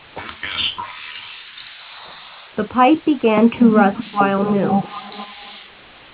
Here is a simulation of what echo sounds like.
signal using an adaptive filter.